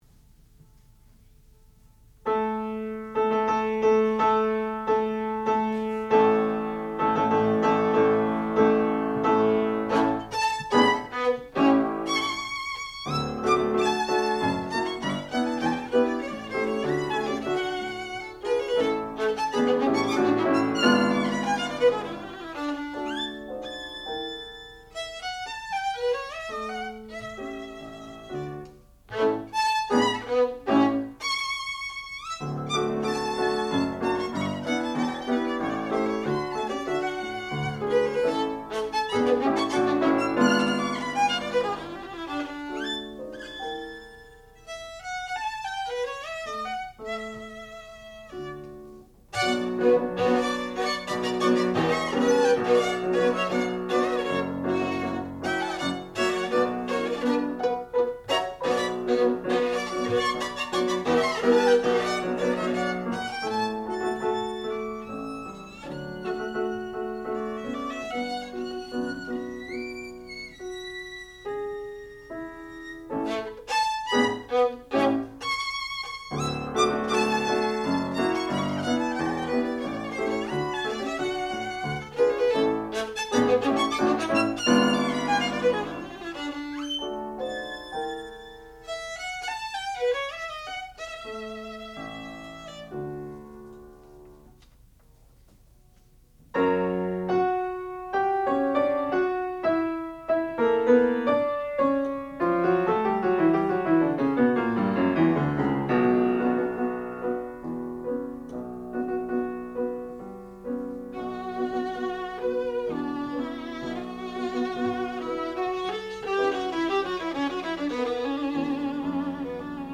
sound recording-musical
classical music
Advanced Recital
violin